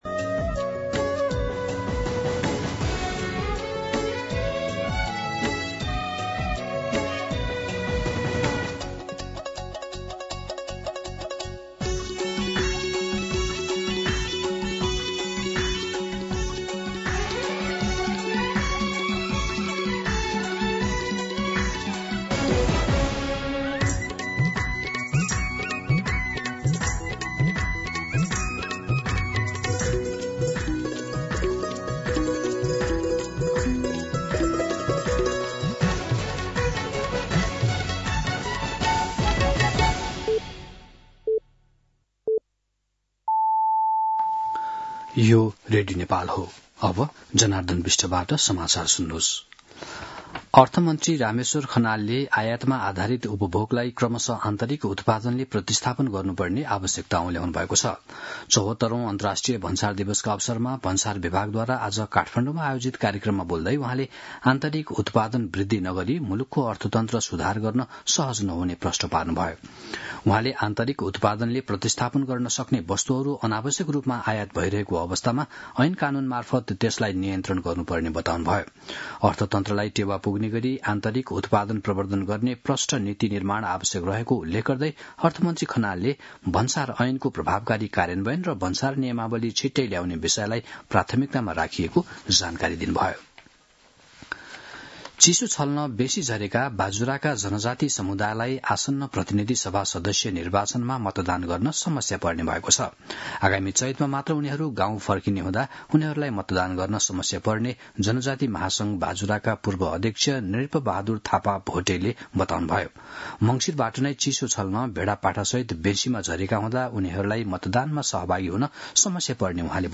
दिउँसो १ बजेको नेपाली समाचार : १२ माघ , २०८२